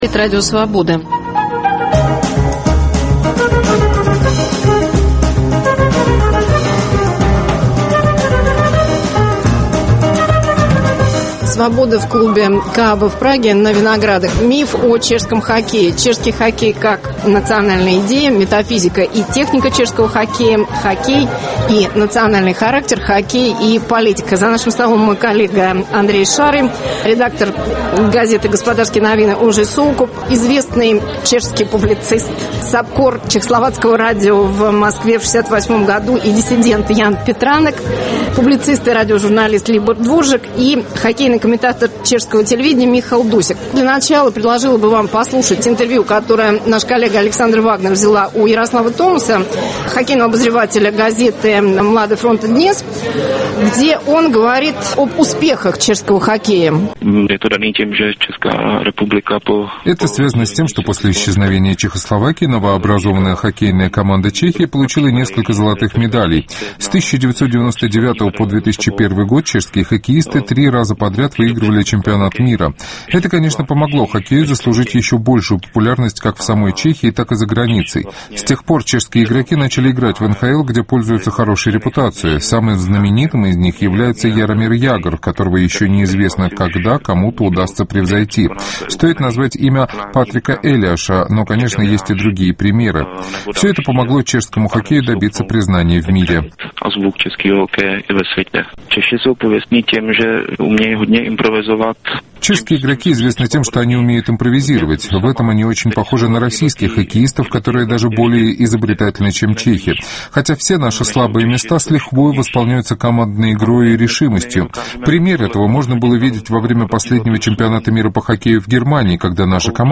Cвобода в клубе "Кааба", Прага Чешский хоккей как национальная идея и культурный миф. Метафизика и техника чешского хоккея.